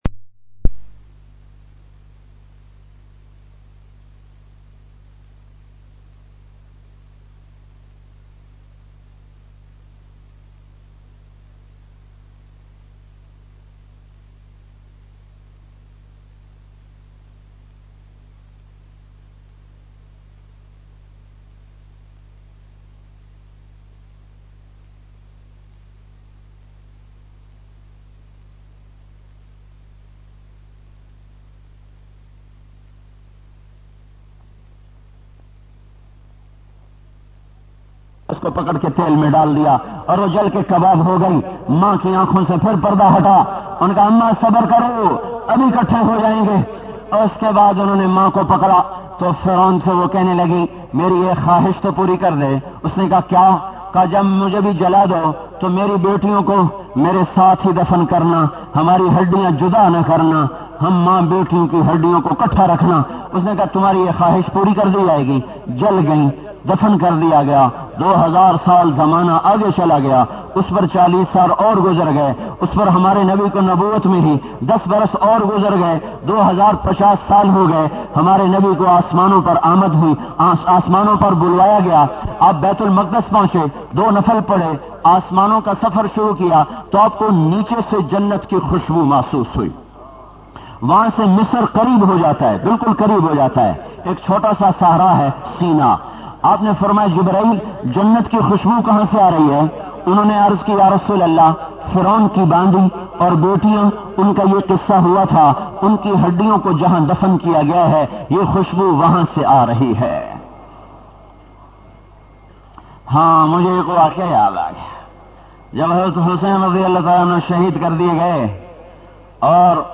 ALLAH ke bandagi bayan mp3 play online & download.